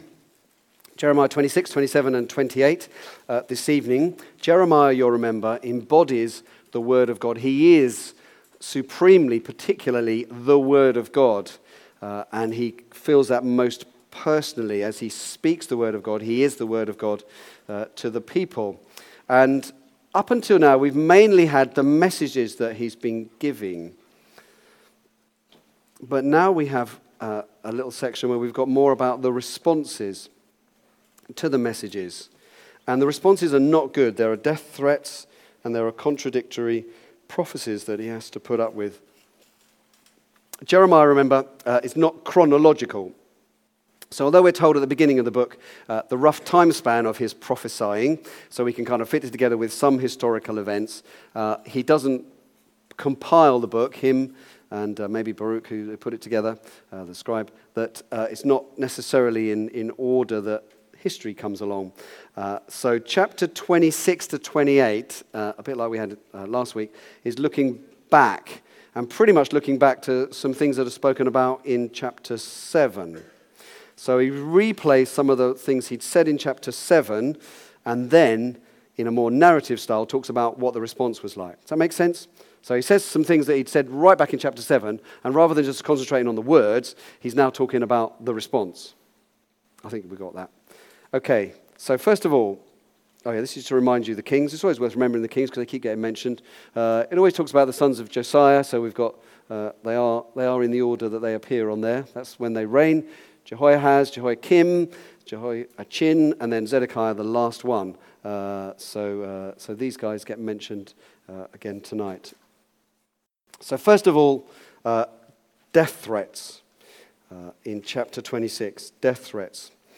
Back to Sermons True prophet preserved